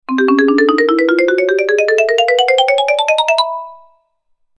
Ascending Marimba Melody For Character Footsteps Sound Effect
An ascending marimba melody indicating a character’s footsteps or stair climbing. Cartoon sounds. Funny sounds.
Genres: Sound Effects
Ascending-marimba-melody-for-character-footsteps-sound-effect.mp3